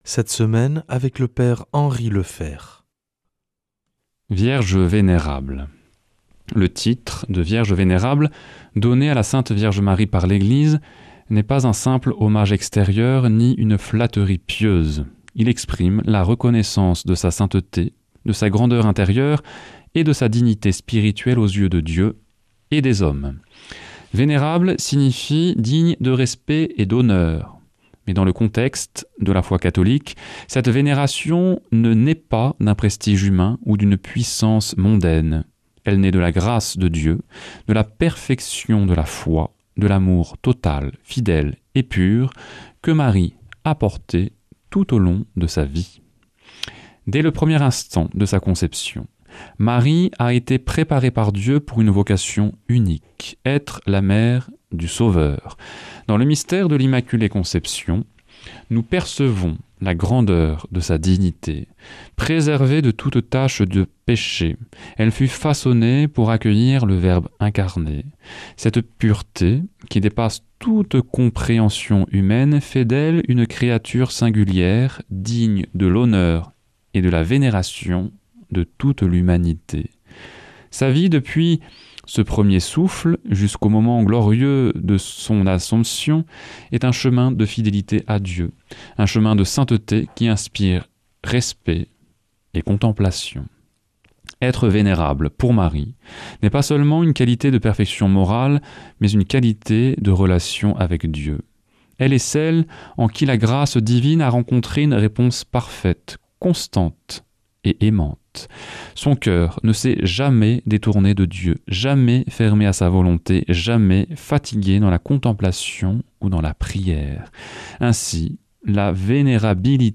vendredi 17 avril 2026 Enseignement Marial Durée 10 min